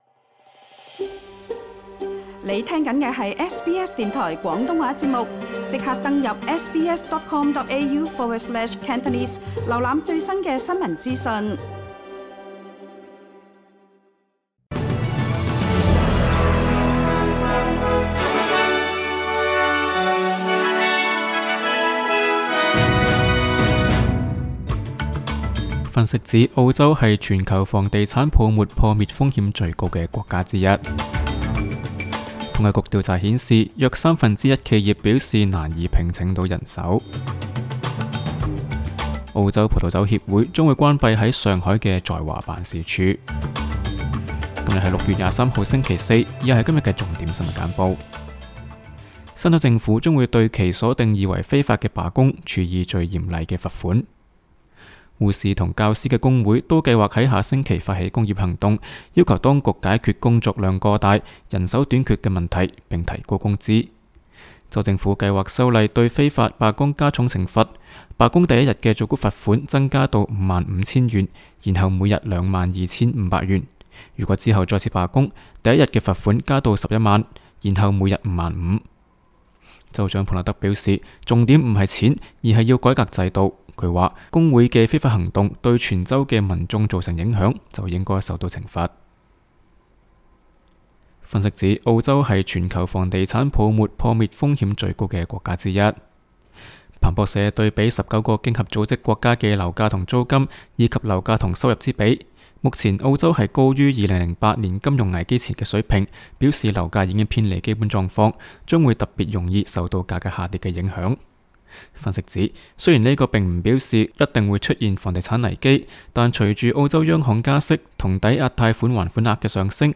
SBS 新闻简报（6月23日）